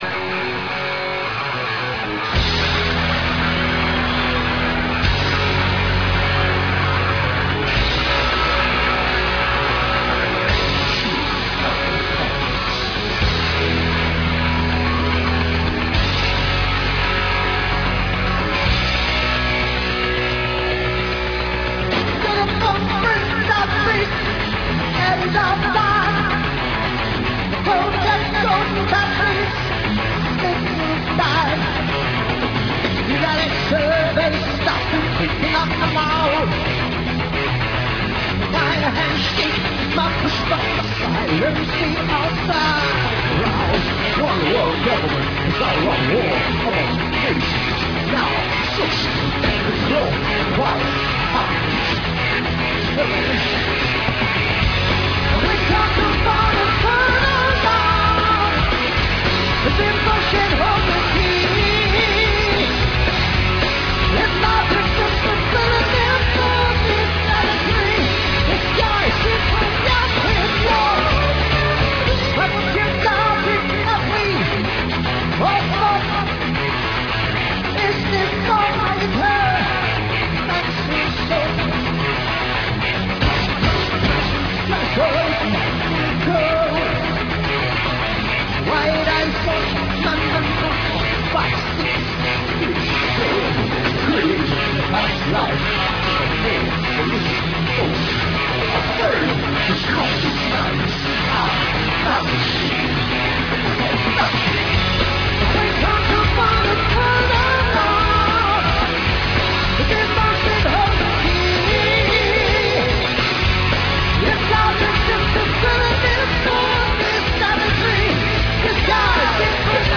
Liveaufnahme vom 24.05.96 in San Jose, CA, USA